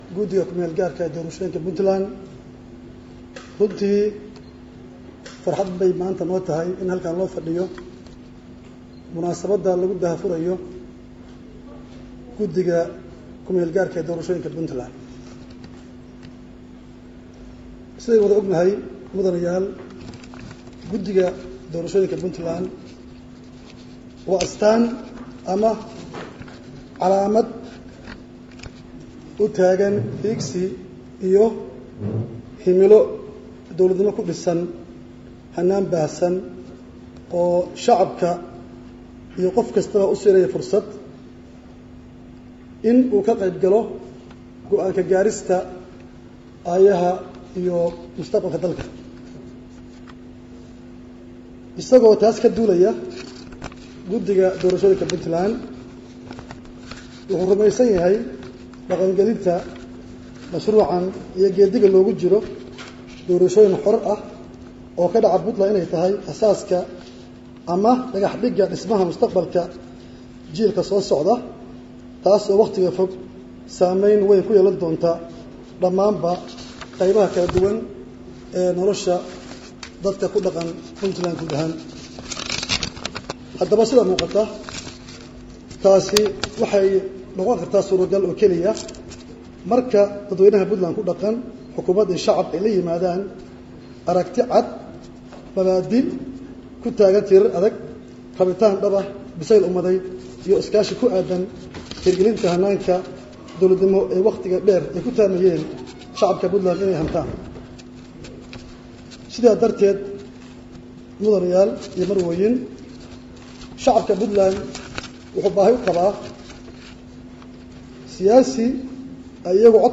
11 May 2016 (Puntlandes) Waxaa maanta lagu qabtay Xarunta Hay’adda PDRC Garowe Munaasabadd lagu daah furayey Guddiga KMG ah e doorashooyinka Punland
Dhagayso Gudoomiyaha gudiga doorashooyinka Puntland Axmed Maxamed Cali (A.Kismaayo)